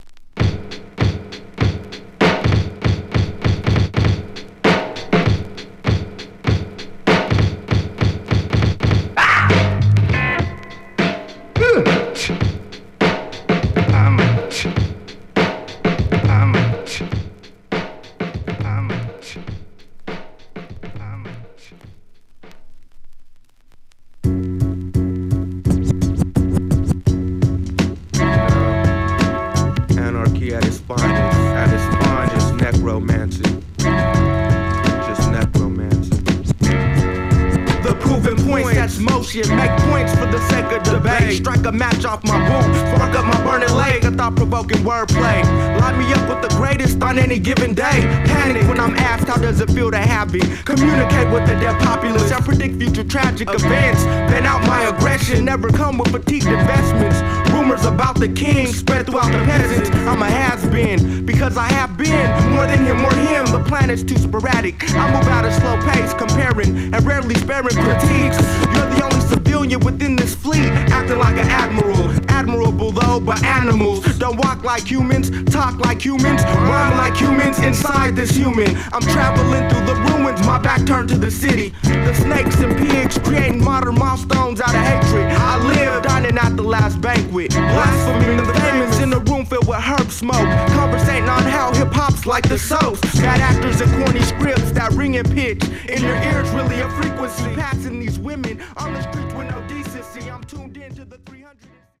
骨太なビート／厳選チョイスされたソウルやジャズなどのサンプル／テクニカルなスクラッチが、さまざまに融合。
※side bキズあり